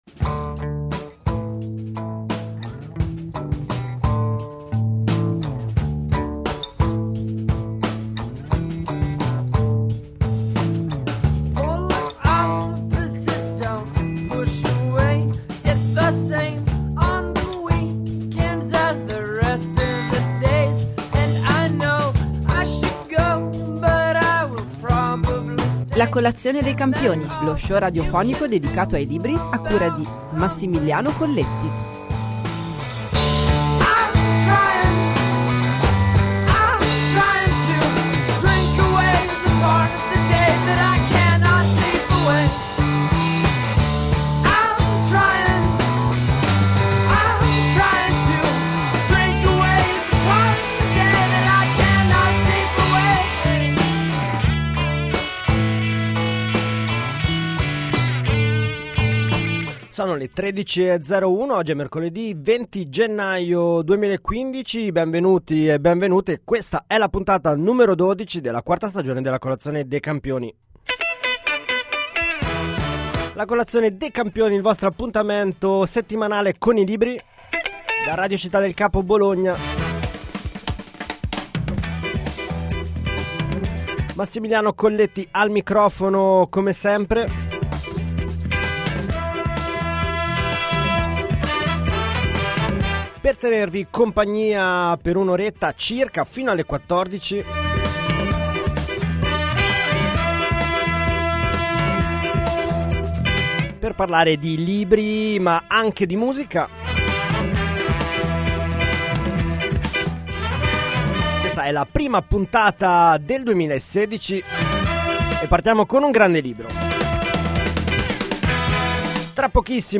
Per la prima puntata del 2016, La colazione dei campioni ospita Tommaso Pincio, autore romano tra i più originali e vincitore dell’ultima edizione del Premio Sinbad assegnato dagli editori indipendenti.
Nel finale, grazie alla pronta domanda di un ascoltatore, Pincio ci svela i segreti nascosti dietro il suo nome.